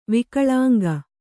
♪ vikaḷānga